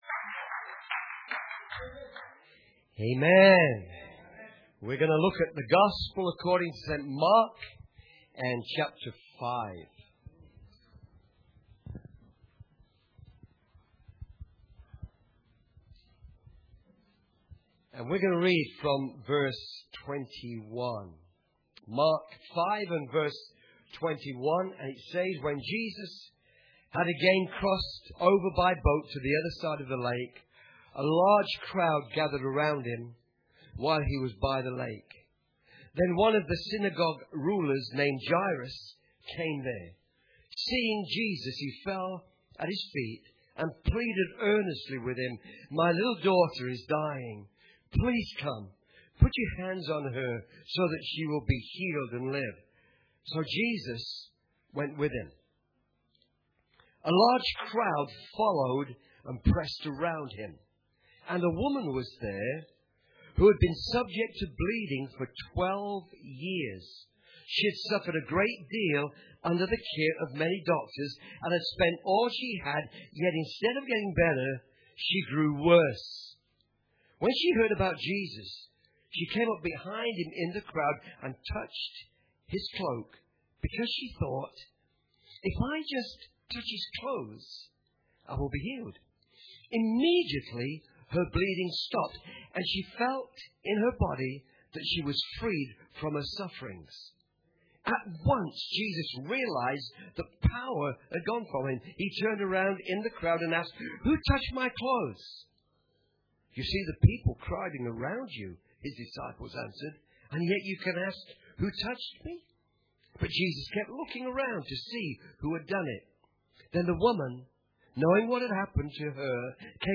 Church Camp 2014 – Friday_Night
Friday_Night Preacher